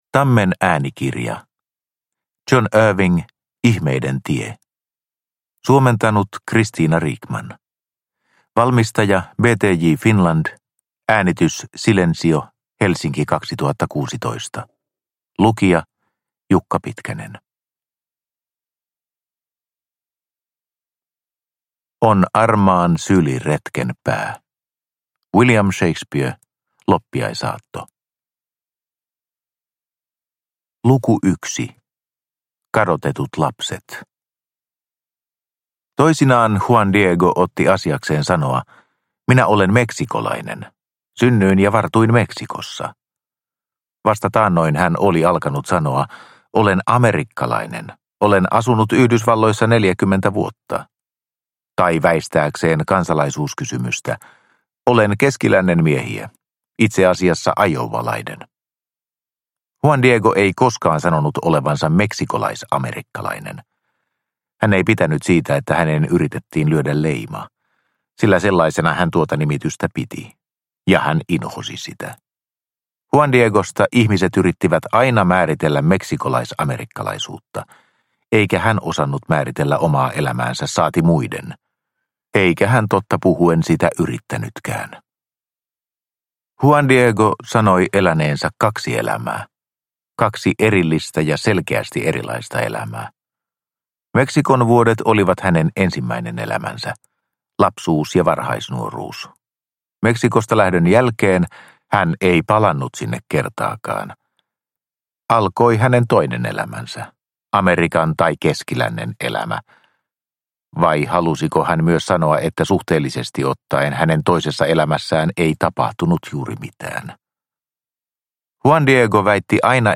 Ihmeiden tie – Ljudbok – Laddas ner